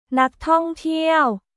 นักท่องเที่ยว　ナック・トン・ティアオ